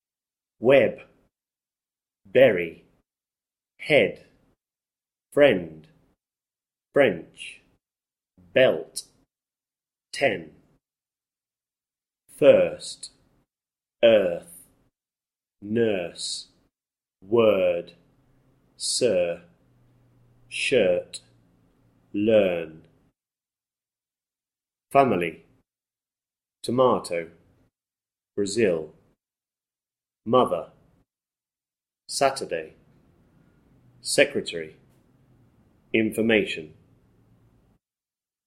Listen to the pronunciation of these sounds in English: Now listen to the words under each sound web first fam i ly bury earth t o mato head nurse Br a zil friend word moth e r French sir S a turday belt shirt secr e tary ten learn inf o rmation Audio �